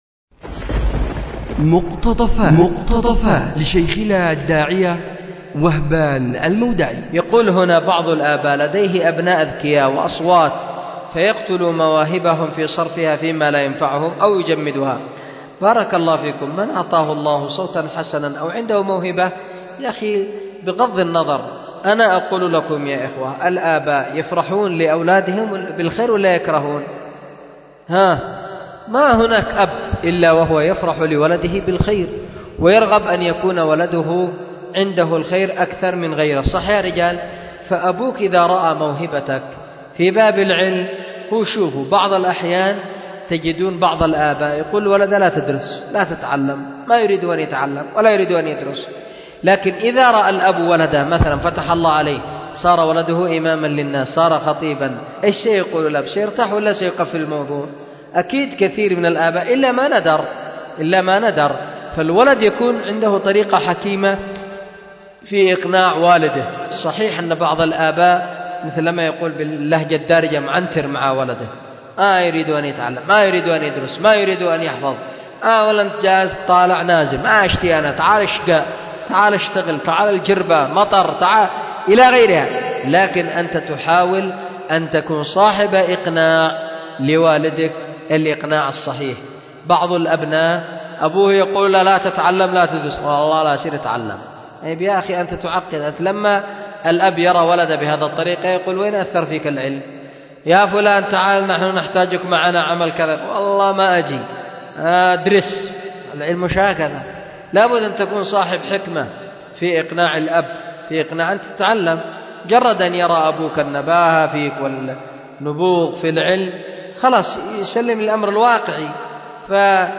أُلقي بدار الحديث للعلوم الشرعية بمسجد ذي النورين ـ اليمن ـ ذمار 1444هـ